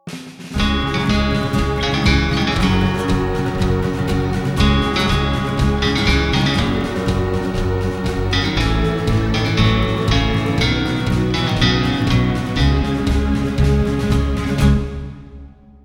Звуки победы, салюта